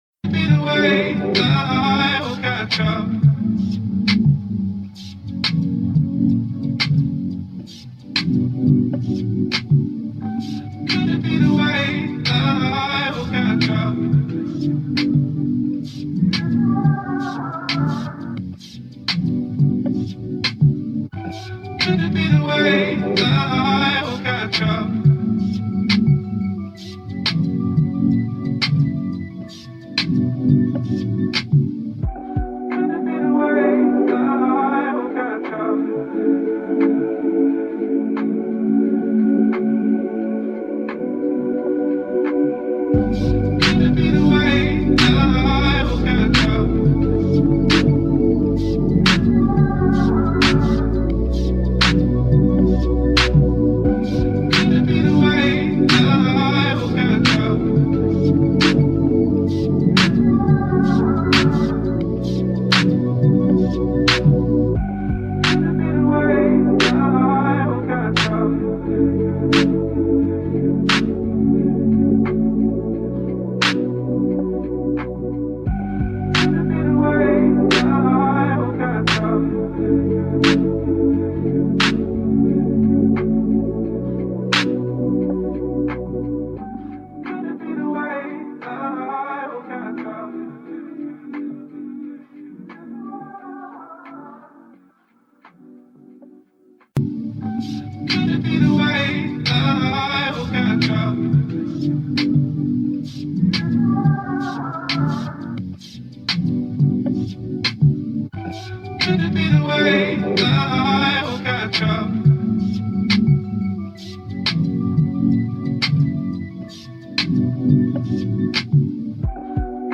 Hip-Hop Instrumental